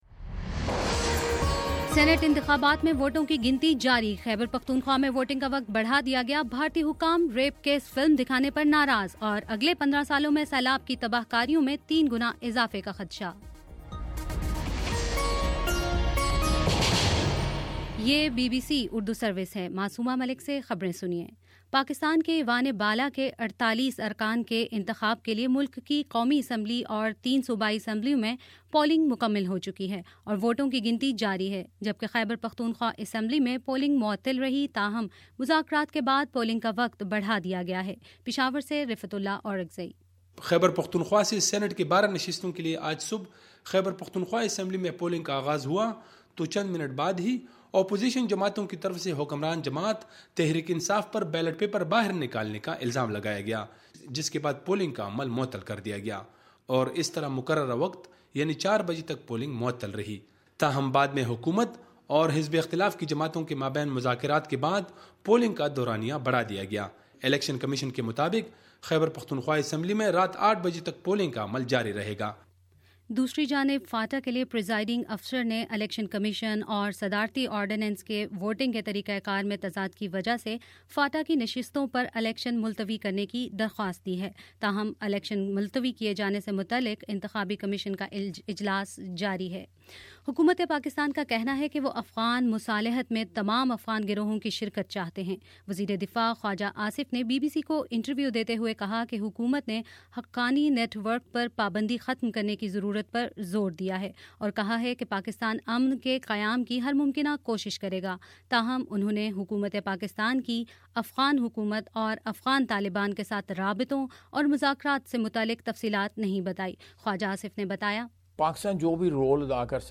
مارچ 05: شام پانچ بجے کا نیوز بُلیٹن
دس منٹ کا نیوز بُلیٹن روزانہ پاکستانی وقت کے مطابق شام 5 بجے، 6 بجے اور پھر 7 بجے۔